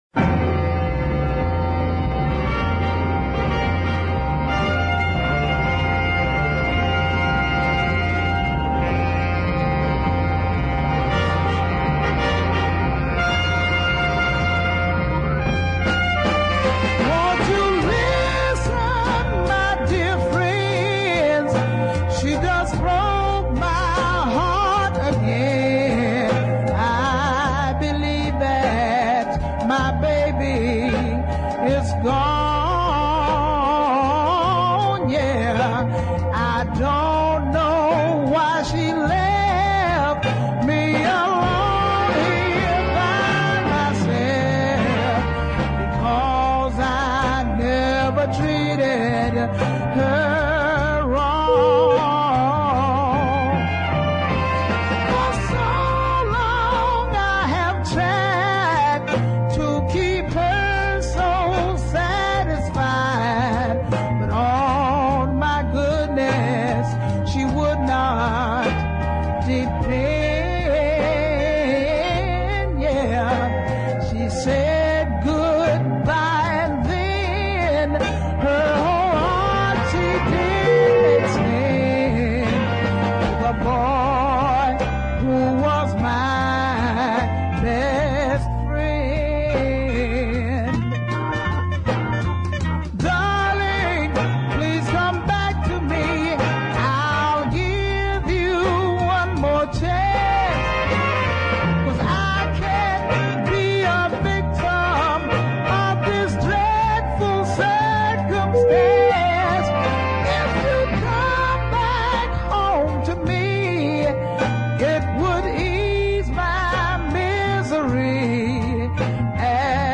R & B / soul band
organ